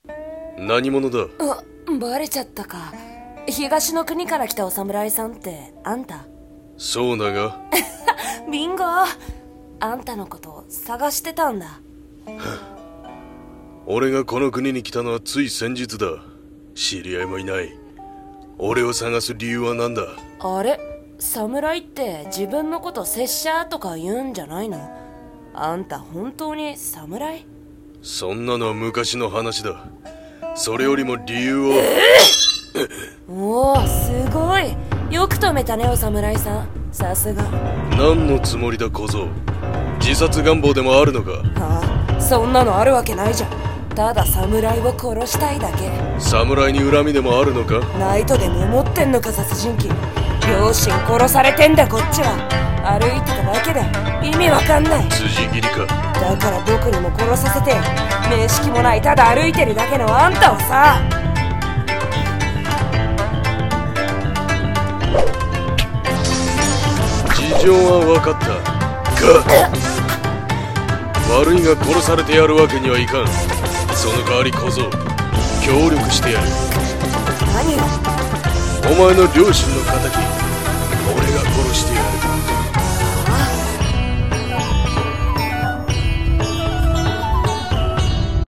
声劇台本「東の国の侍」